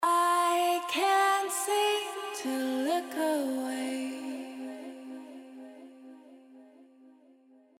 Vocals & Guitars
Vocals No Saturation:
07-Vocals-No-Saturation.mp3